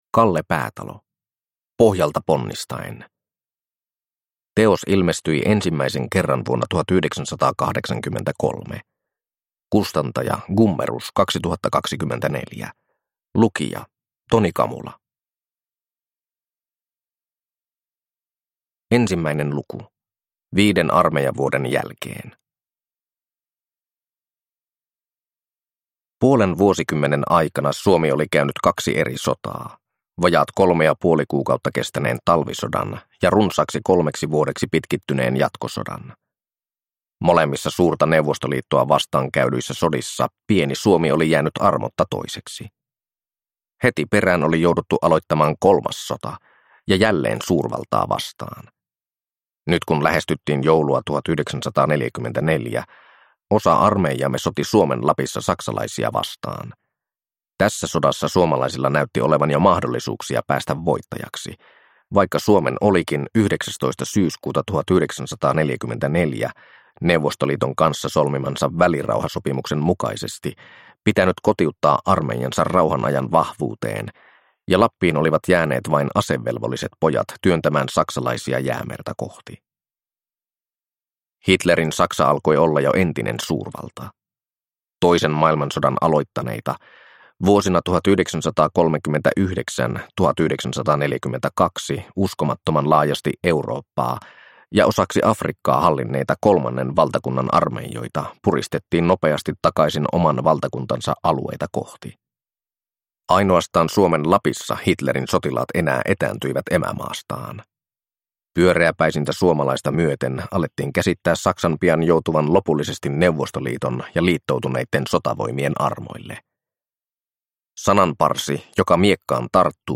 Pohjalta ponnistaen (ljudbok) av Kalle Päätalo